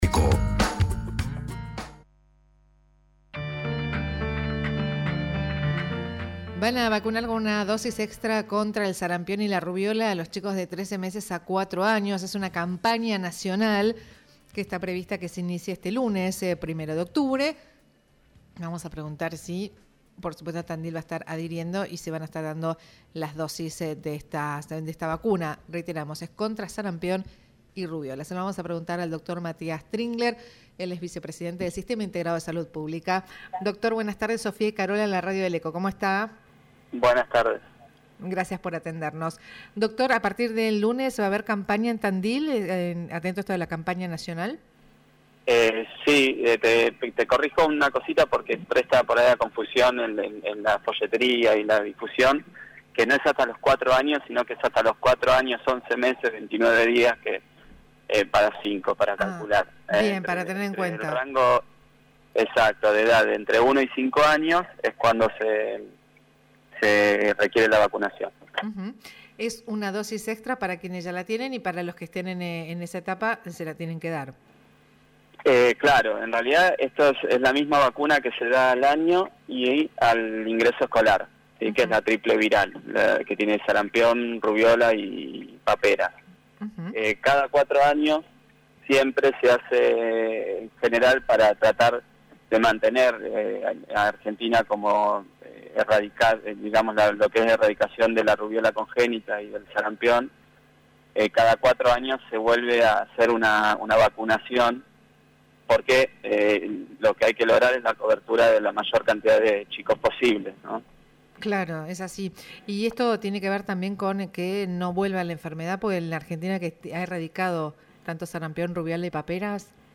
Entrevista a Matías Tringler | Buenas y Santas